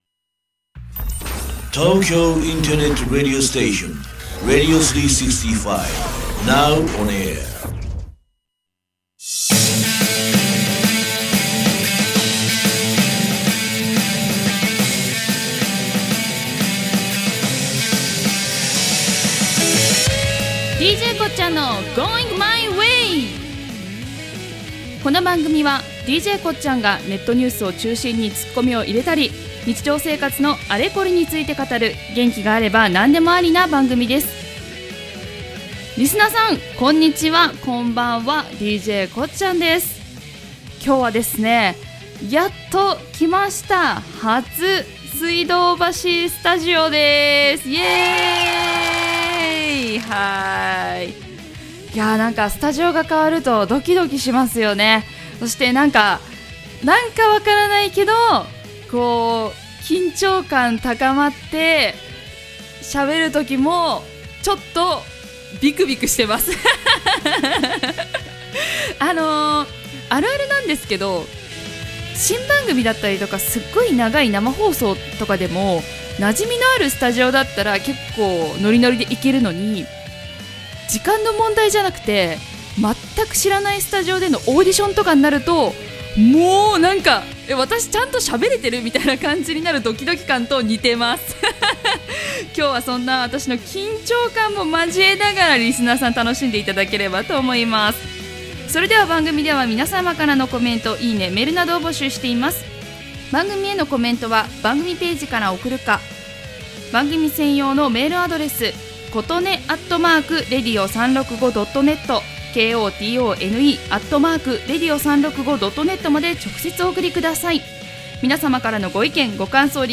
水道橋スタジオで初収録でした！